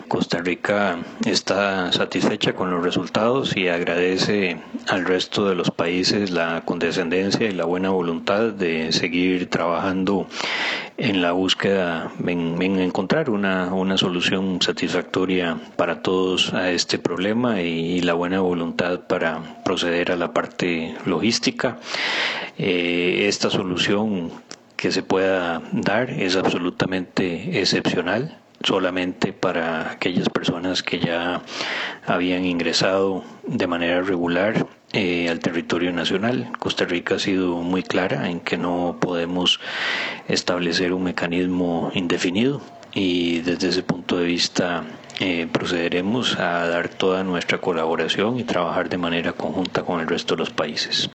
Declaraciones del Canciller de Costa Rica